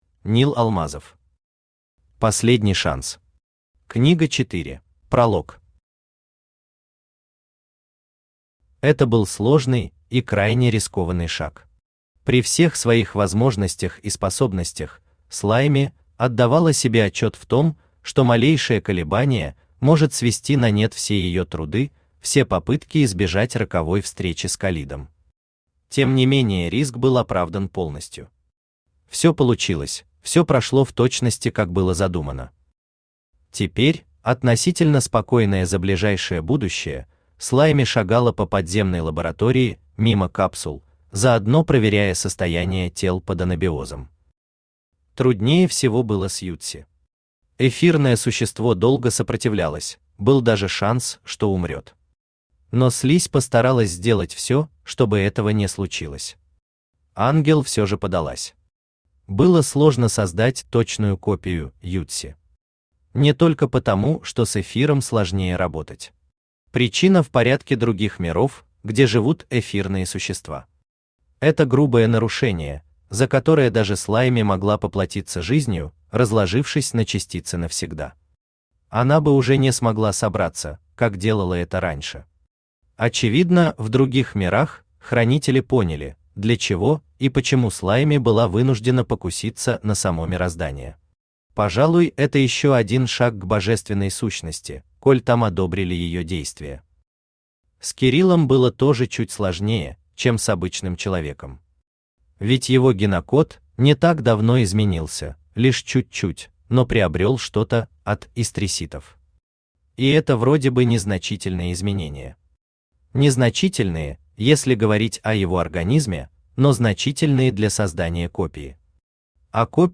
Книга 4 Автор Нил Алмазов Читает аудиокнигу Авточтец ЛитРес.